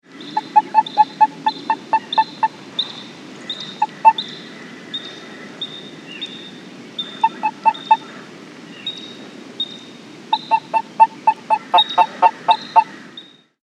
Sounds of California Toad - Anaxyrus boreas halophilus
The following recordings were made on a sunny afternoon in early March in Contra Costa County at the edge of a small cattle pond.
Sound   This is a 13 second recording of the sounds of one toad, recorded from a short distance outside the entrance to a California Ground Squirrel burrow (shown to the right.)
A California Ground Squirrel is heard calling in the background, along with crows and other birds.   california toad habitat